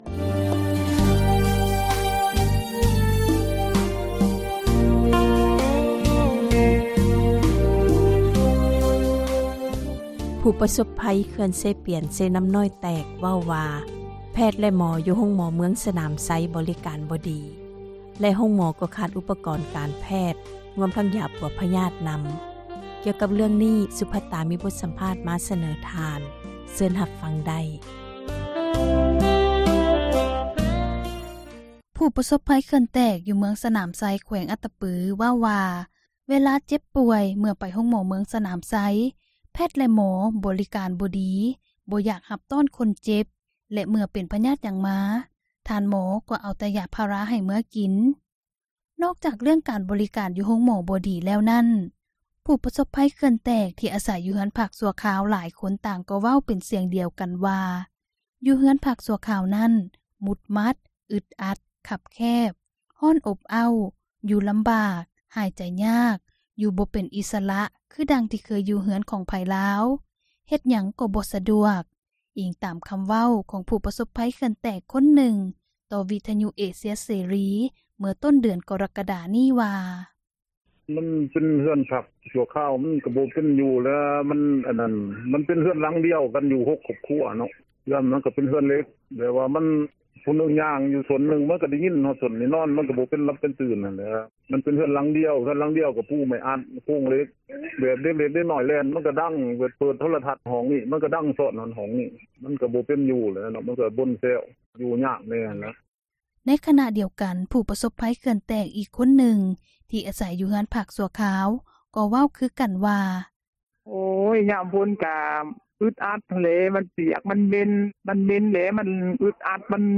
ທີ່ທ່ານໄດ້ຮັບຟັງສິ້ນສຸດລົງໄປນັ້ນ ແມ່ນການສັມພາດ ຊີວິດການເປັນຢູ່ ຂອງປະຊາຊົນ ຜູ້ປະສົພພັຍ ເຂື່ອນເຊປຽນ-ເຊນໍ້ານ້ອຍ ແຕກ ທີ່ເມືອງສນາມໄຊ ແຂວງອັດຕະປື ເມື່ອຕົ້ນເດືອນ ກໍຣະກະດາ 2019 ນີ້.